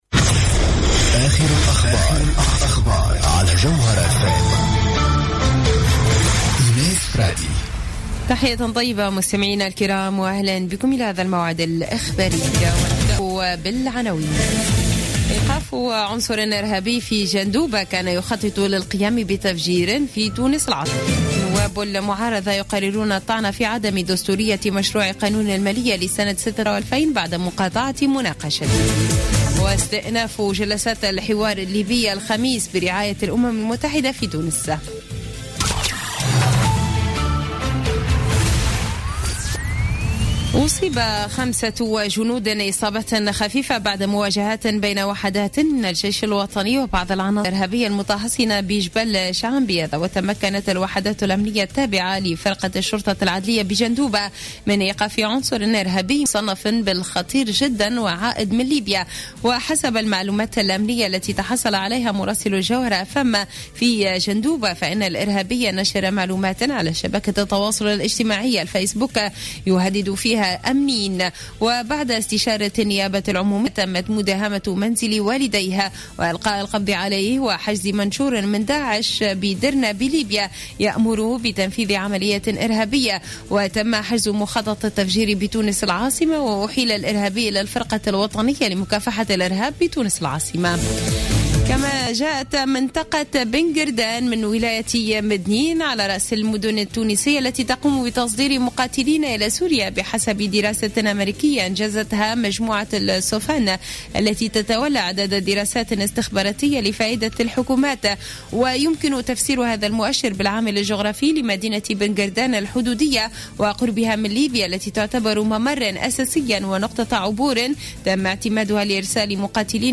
نشرة أخبار منتصف الليل ليوم الخميس 10 ديسمبر 2015